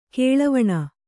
♪ kēḷavaṇa